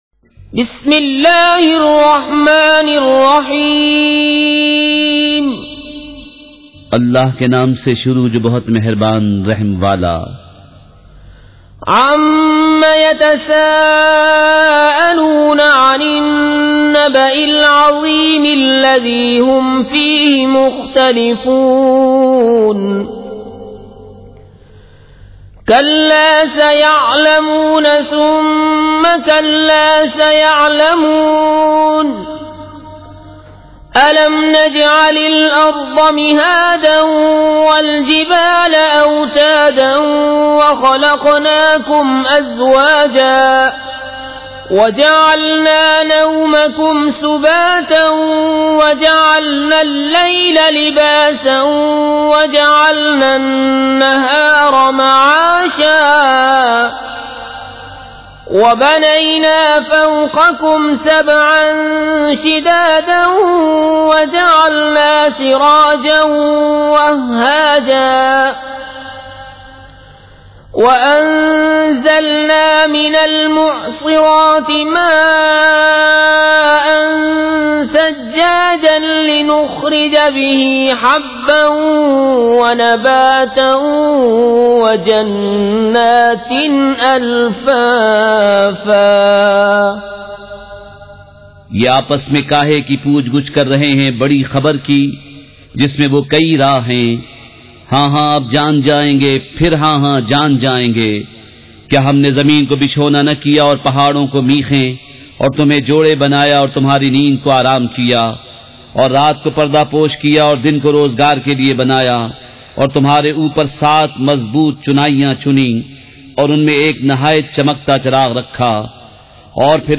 سورۃ النبإ مع ترجمہ کنزالایمان ZiaeTaiba Audio میڈیا کی معلومات نام سورۃ النبإ مع ترجمہ کنزالایمان موضوع تلاوت آواز دیگر زبان عربی کل نتائج 1747 قسم آڈیو ڈاؤن لوڈ MP 3 ڈاؤن لوڈ MP 4 متعلقہ تجویزوآراء